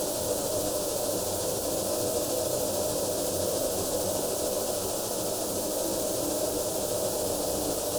Index of /musicradar/stereo-toolkit-samples/Tempo Loops/120bpm
STK_MovingNoiseC-120_02.wav